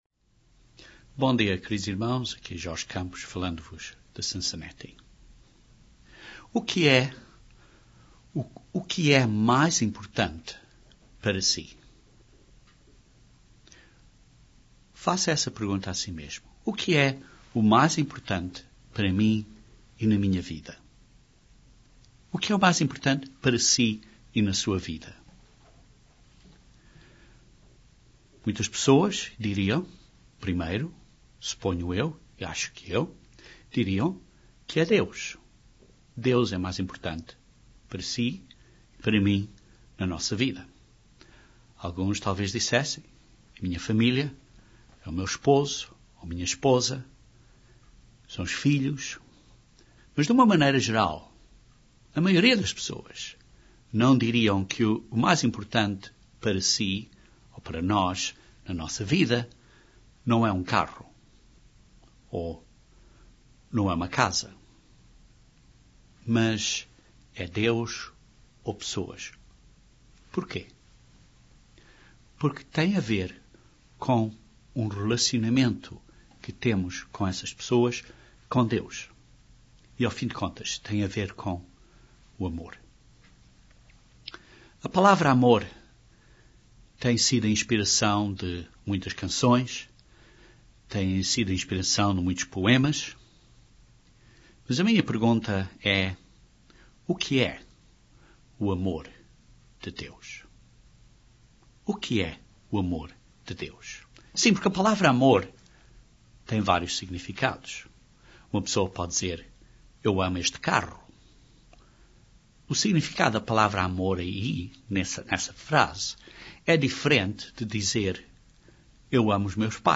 Temos um entendimento correto do amor de Deus? Este sermão analisa este importante princípio da vida Cristã.